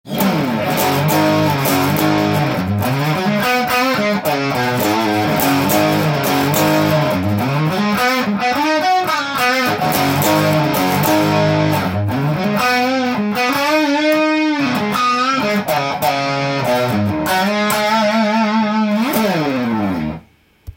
ハムバッカーで歪ませて弾いてみました
逆にハムバッカーの歪んだ音は、安定感抜群でハードロックのリフや